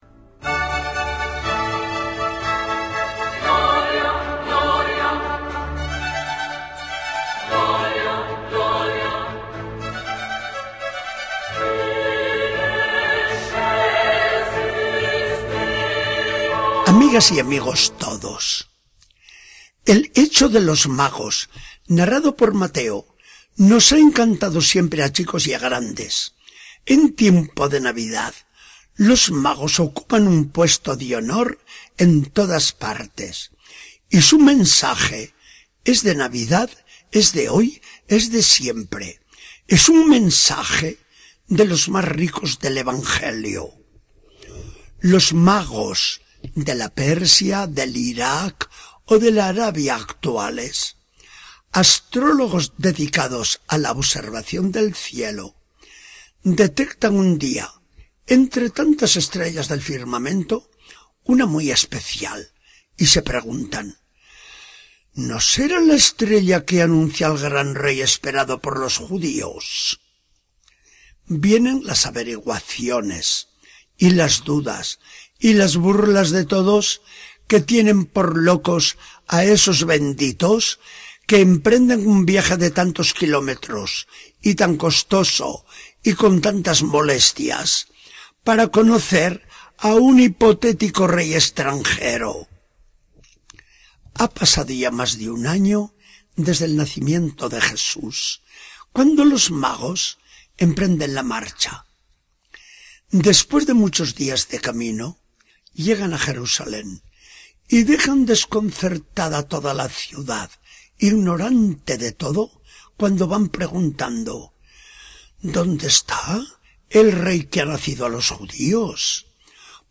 Charla del día 5 de enero de 2014. Del Evangelio según San Mateo 2, 1-12.